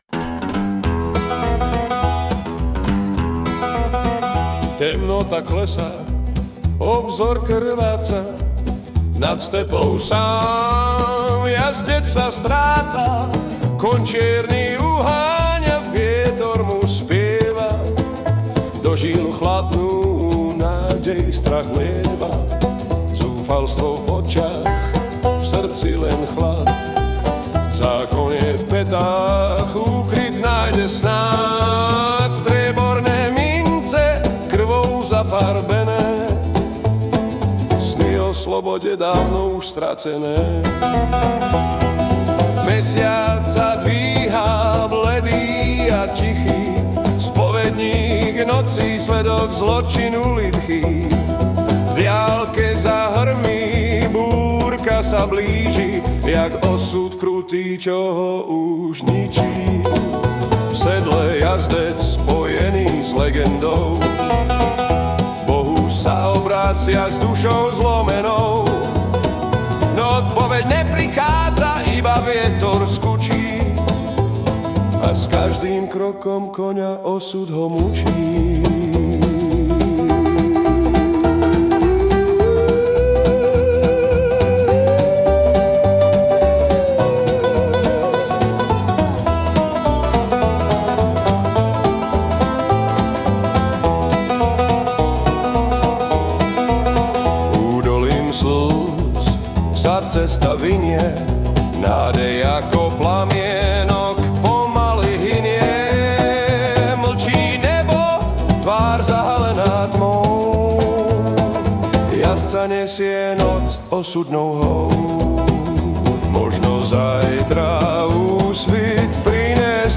Balady, romance » Romantické
Anotace: Pokus o Country
HUdba a spev AI
to je energie po ránu :)